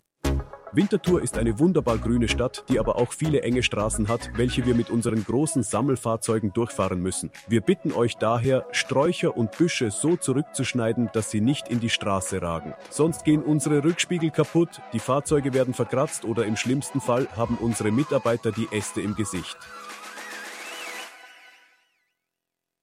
Tipps und Tricks (made with AI) 16th January 2026 • Trittbrett-Talk - Abfall-Geschichten aus Winterthur • Stadt Winterthur